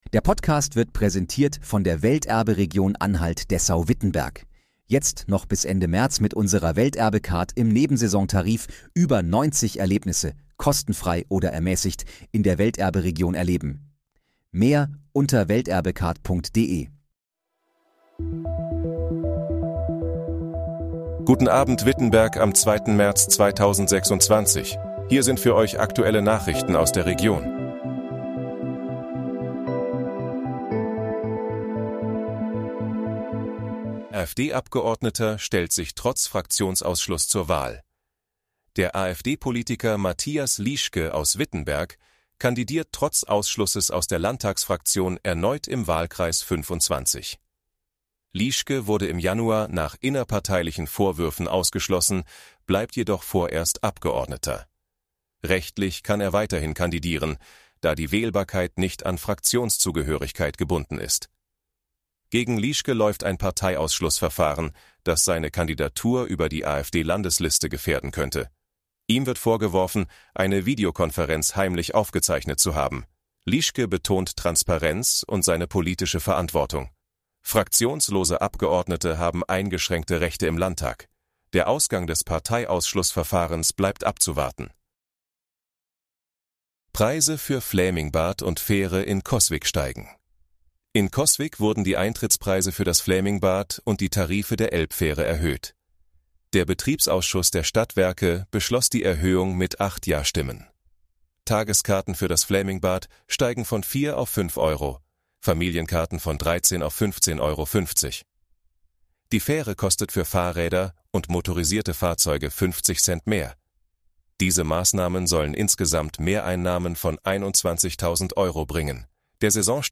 Guten Abend, Wittenberg: Aktuelle Nachrichten vom 02.03.2026, erstellt mit KI-Unterstützung